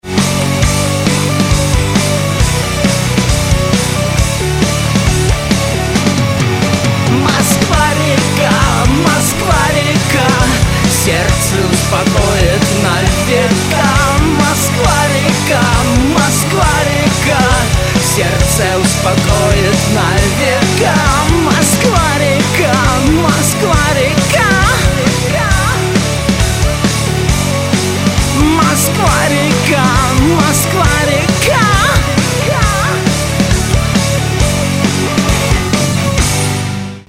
• Качество: 192, Stereo
Хороший новый рок